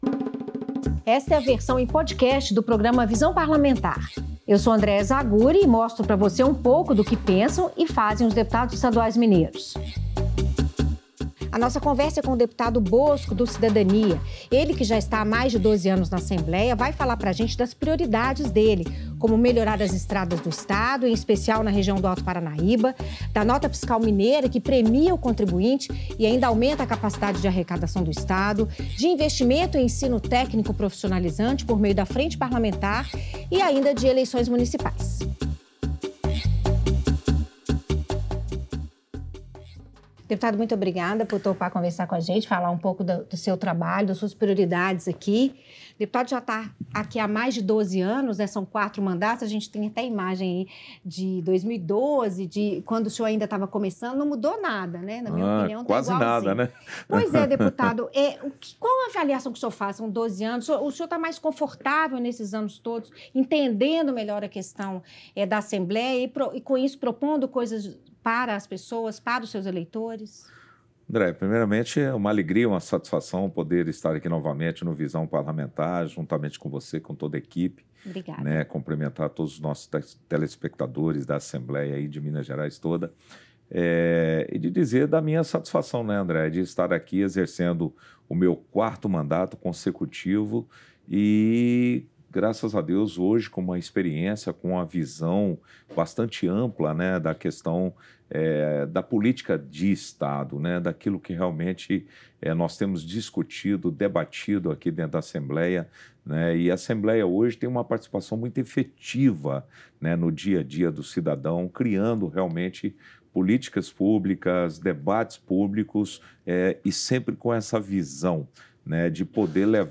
O deputado fala sobre proposições de autoria dele que viraram leis, como a que cria um programa de incentivo fiscal que vai premiar consumidores e evitar a sonegação de impostos. Bosco falou também sobre a situação das rodovias mineiras, sobre eleições municipais e sobre a Frente Parlamentar em Defesa do Ensino Técnico e Profissionalizante do Estado de Minas Gerais.